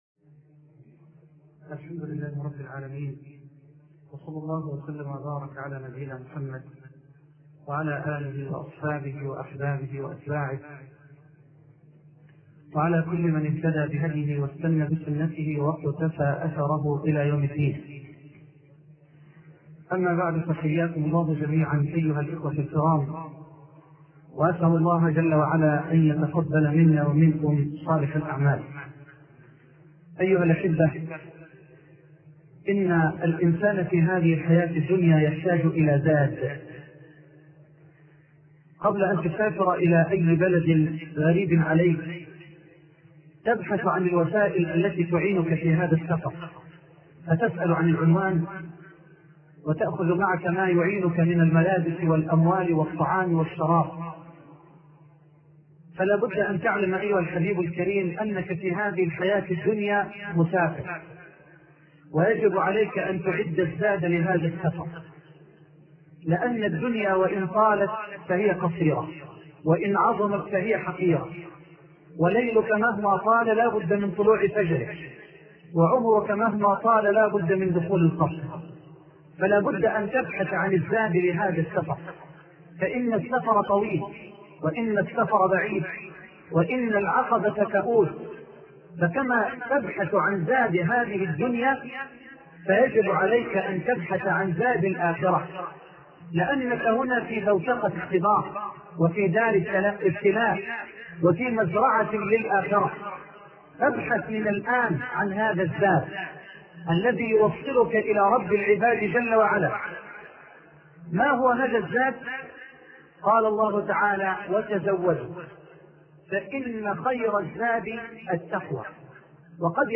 شبكة المعرفة الإسلامية | الدروس | خير الزاد |محمد حسان